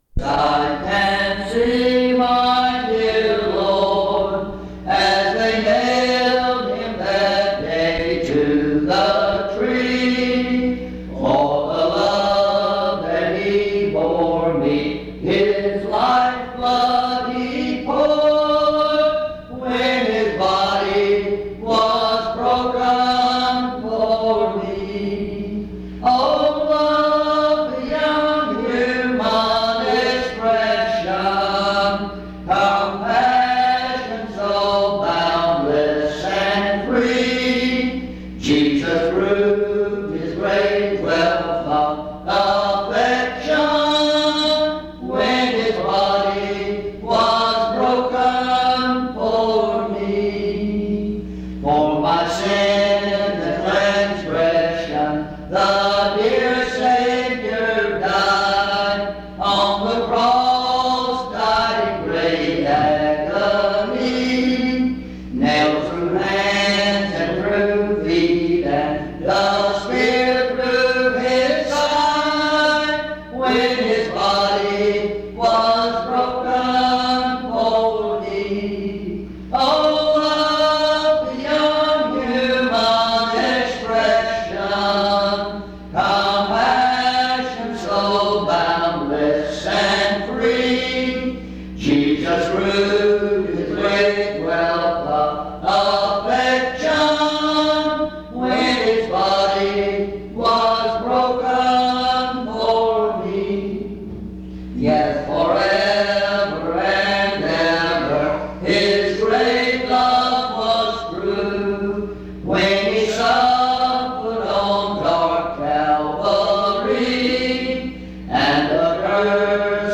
In Collection: Reidsville/Lindsey Street Primitive Baptist Church audio recordings Miniaturansicht Titel Hochladedatum Sichtbarkeit Aktionen PBHLA-ACC.001_079-A-01.wav 2026-02-12 Herunterladen PBHLA-ACC.001_079-B-01.wav 2026-02-12 Herunterladen